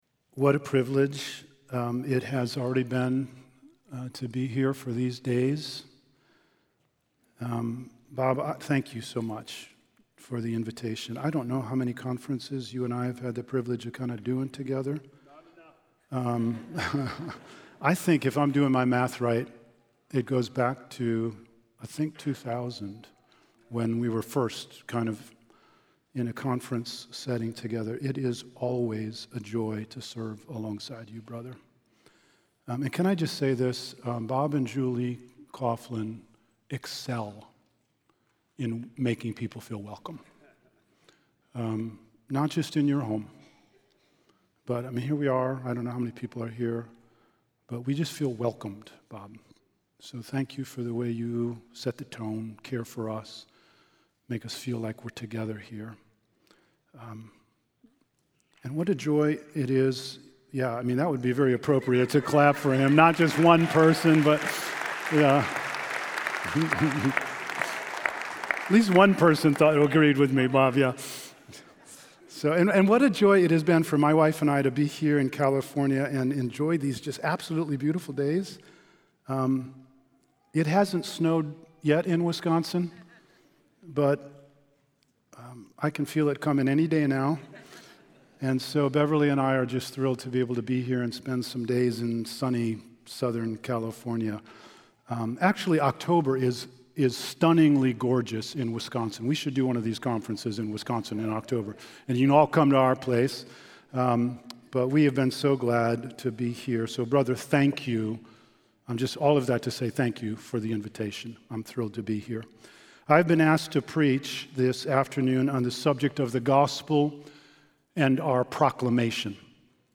Conference Messages
California Conference 2025: Your Gathering and the Gospel